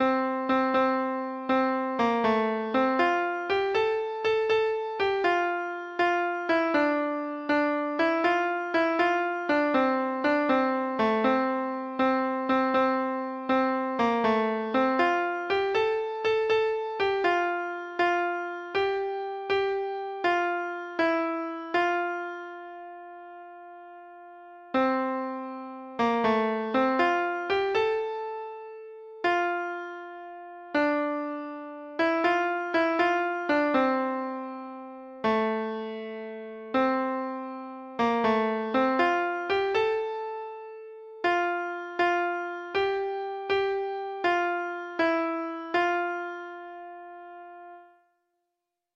Traditional Trad. John Brown's Body Treble Clef Instrument version
Folk Songs from 'Digital Tradition' Letter J John Brown's Body
Traditional Music of unknown author.